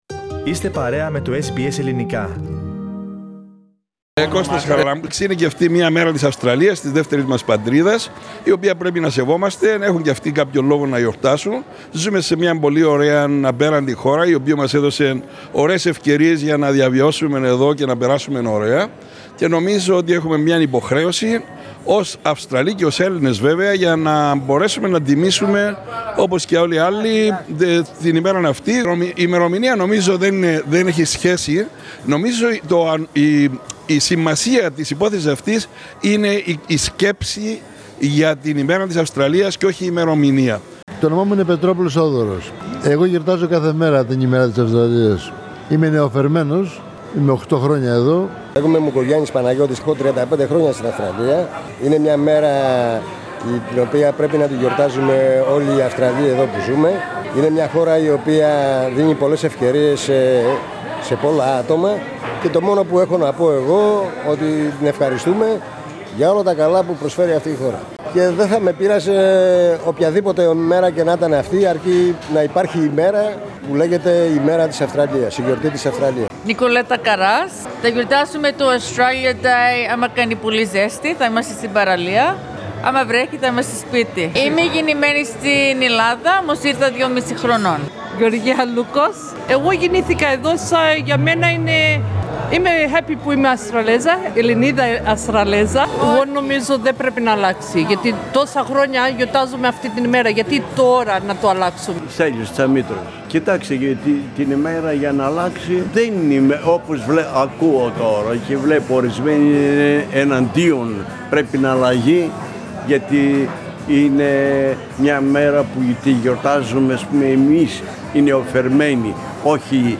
Melbourne Greeks interviewed in Oakleigh Source: SBS Greek